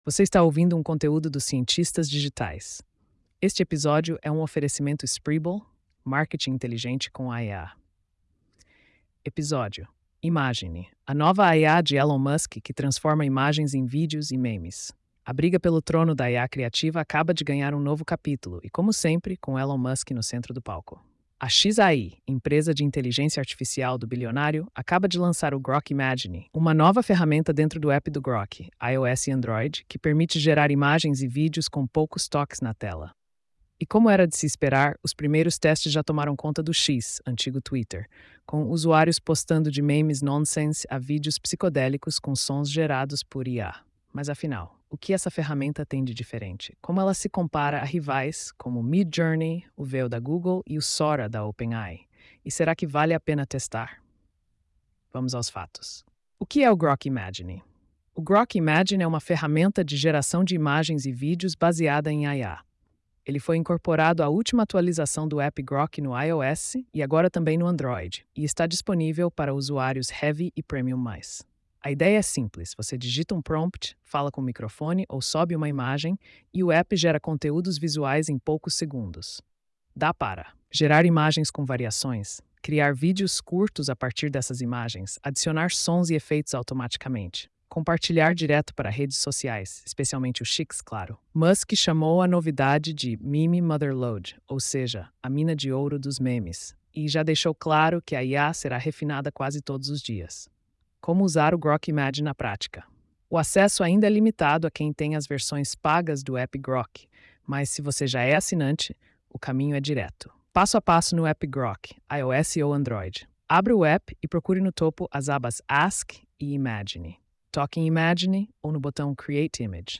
post-4164-tts.mp3